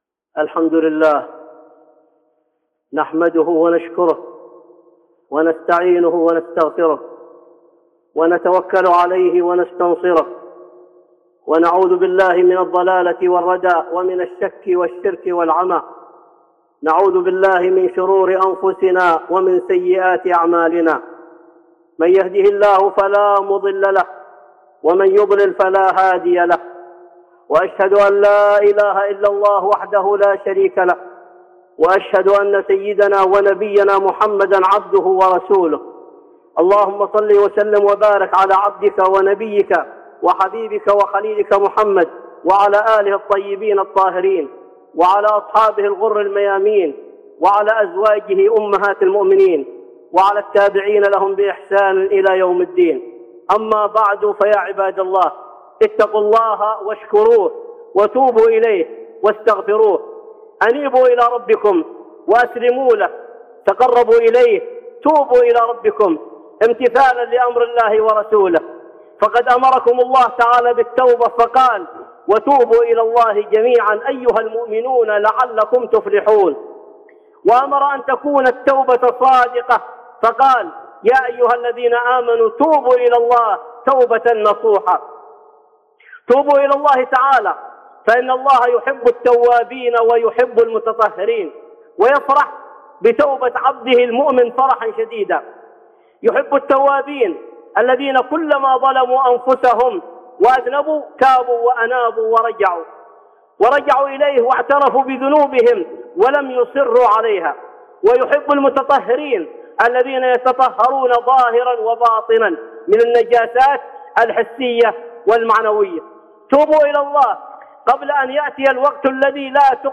(خطبة جمعة) التوبة